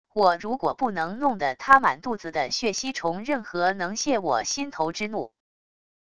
我如果不能弄得他满肚子的血吸虫任何能泄我心头之怒wav音频生成系统WAV Audio Player